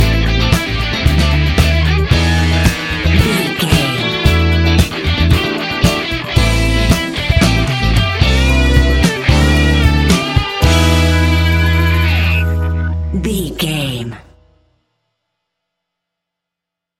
Ionian/Major
A♭
house
electro dance
synths
techno
trance